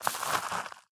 Minecraft Version Minecraft Version 1.21.5 Latest Release | Latest Snapshot 1.21.5 / assets / minecraft / sounds / block / composter / ready1.ogg Compare With Compare With Latest Release | Latest Snapshot